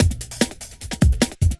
41 LOOP01 -L.wav